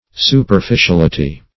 Superficiality \Su`per*fi`ci*al"i*ty\, n. [Cf. F.